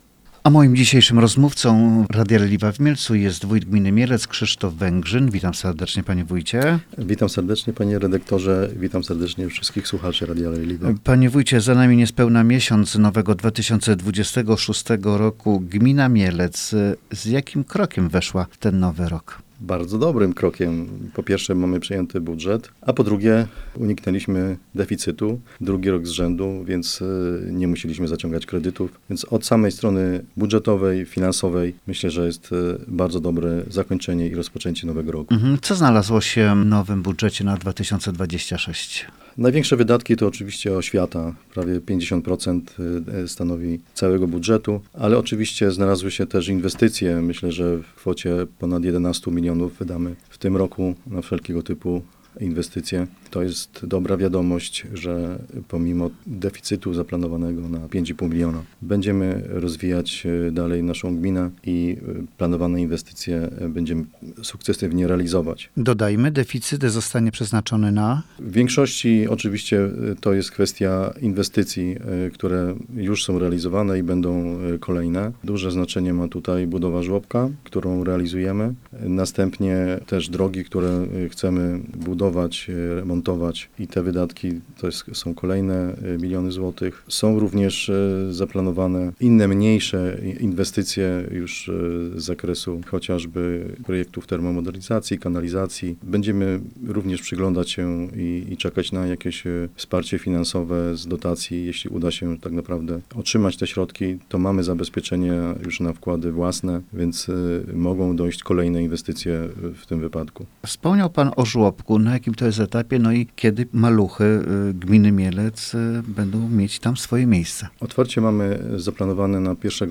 Gościem mieleckiej redakcji Radia Leliwa był wójt Gminy Mielec, Krzysztof Węgrzyn
rozmowa-wojt-gminy-mielec-1.mp3